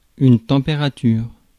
Ääntäminen
Ääntäminen France: IPA: [tɑ̃.pe.ʁa.tyʁ] Haettu sana löytyi näillä lähdekielillä: ranska Käännös Ääninäyte Substantiivit 1. temperature US Suku: f .